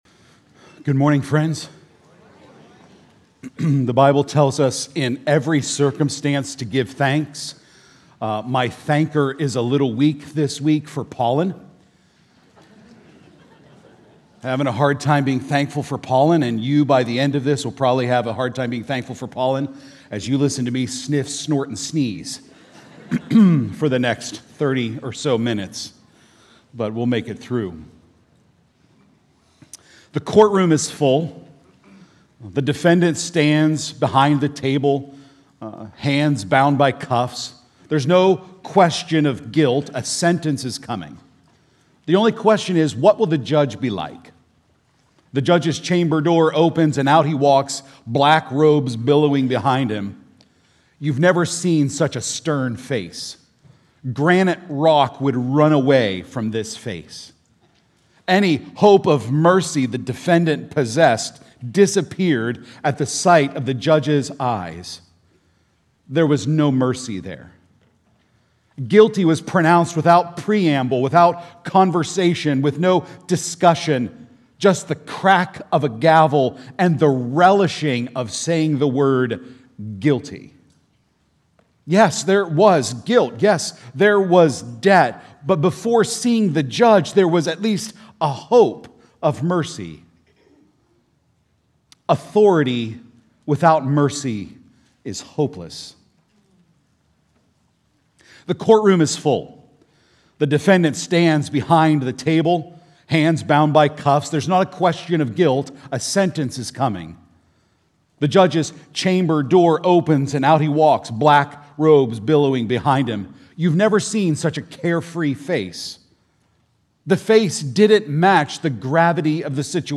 Podcast Sermons Archive North Hills Church